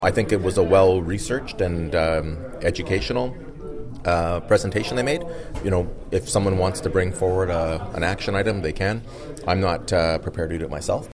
Following the meeting Mayor Mitch Panciuk commented to Quinte News.
MEYERS-PIER-MAYOR.mp3